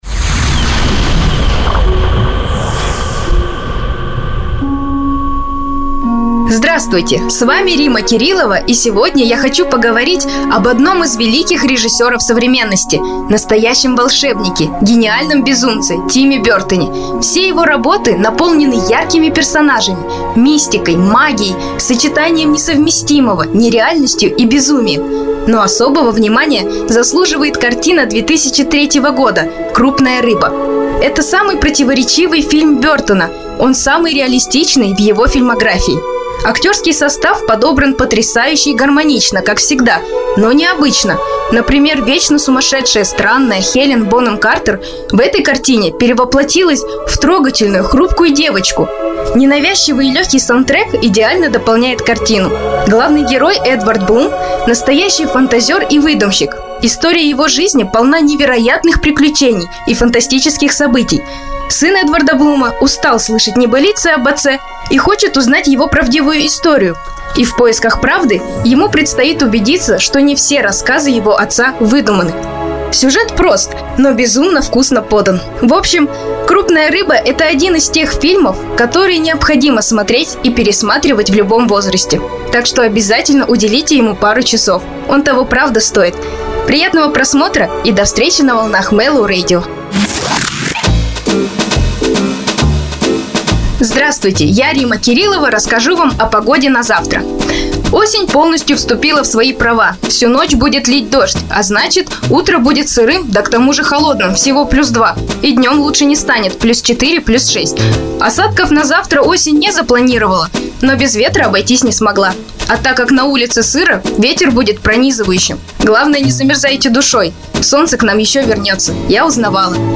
Женский
Сопрано